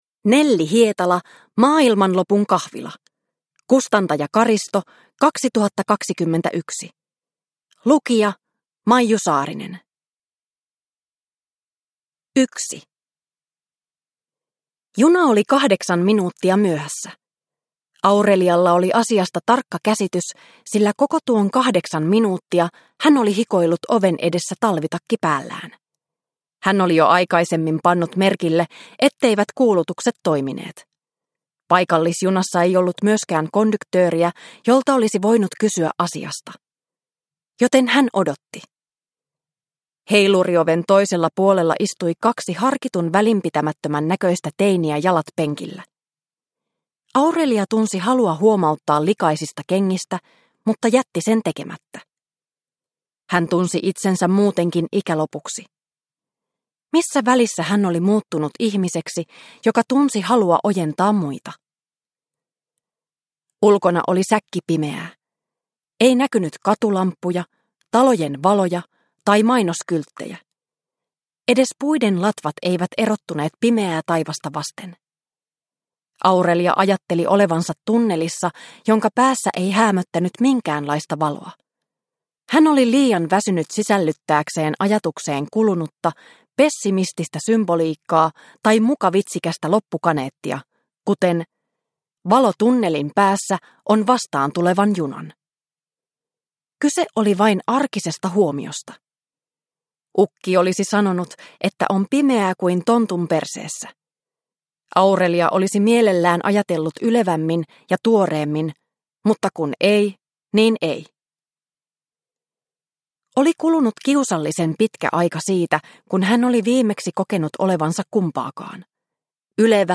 Maailmanlopun kahvila – Ljudbok – Laddas ner